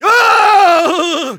Engineer_paincrticialdeath01_de.wav